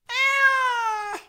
meow1.wav